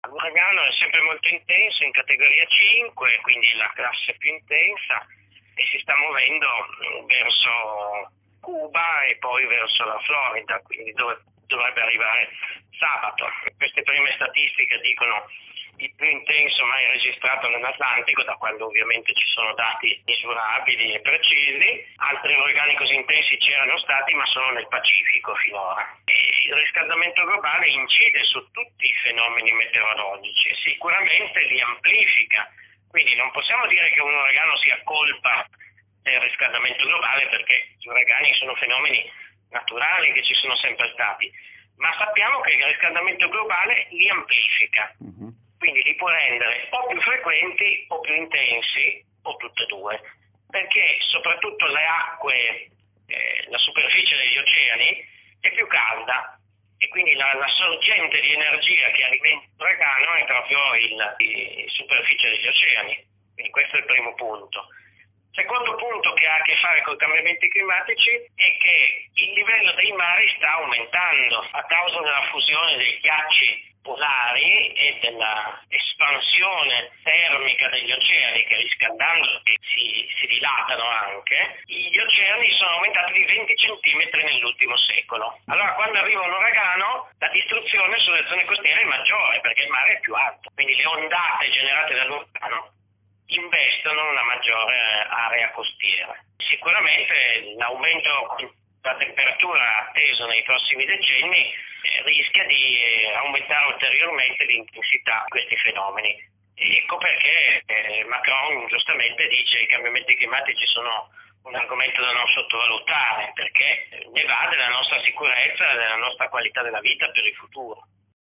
Il climatologo Mercalli a Lumsanews
Il parere di Luca Mercalli, autore della rubrica “Pillole di Mercalli” sul canale Rai News.
Intervista-Luca_Mercalliokok.mp3